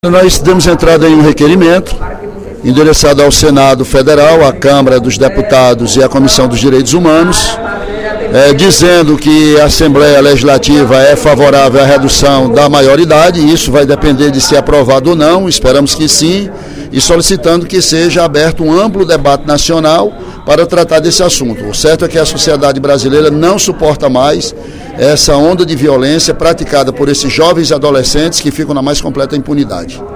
O deputado Ely Aguiar (PSDC) informou, durante o primeiro expediente da sessão plenária desta terça-feira (16/04), que deu entrada hoje a um requerimento endereçado ao Senado e à Câmara Federal, cientificando-os de que o Legislativo cearense é favorável à redução da maioridade penal de 18 para 16 anos.